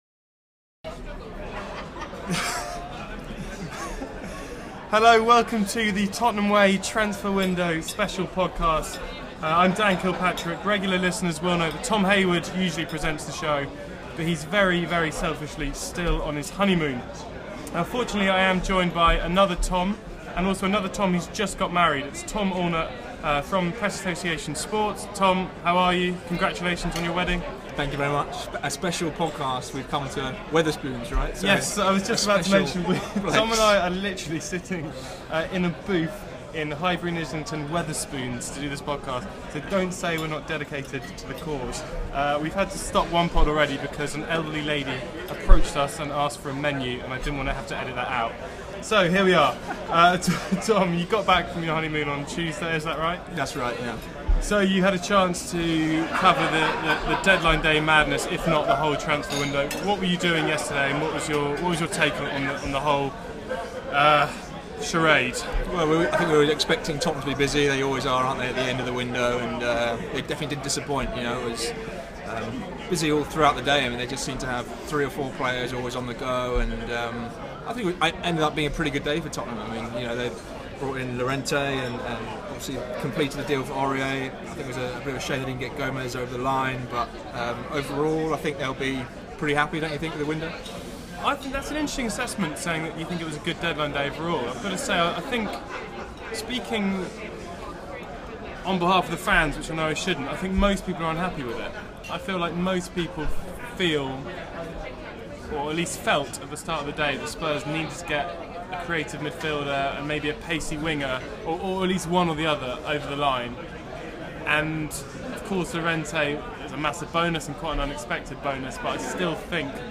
in a booth at Wetherspoons.